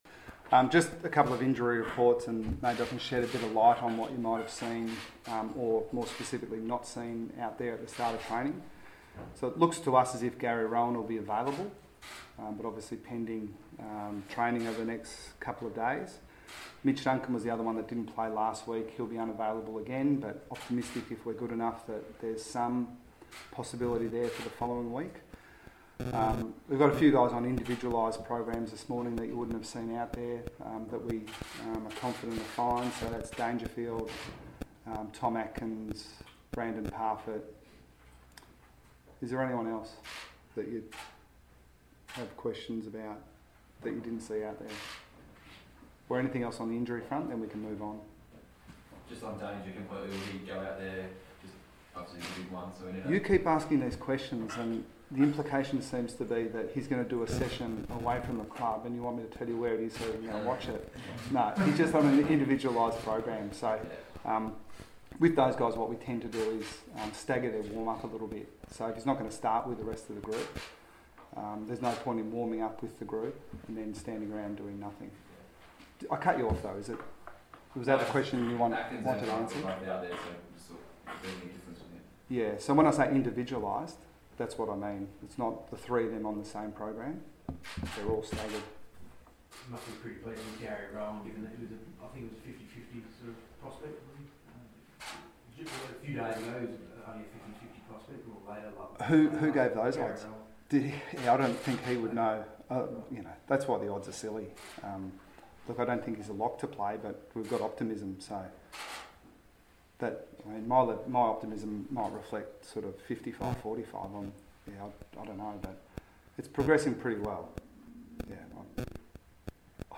Geelong coach Chris Scott faced the media ahead of Friday night's preliminary against Richmond.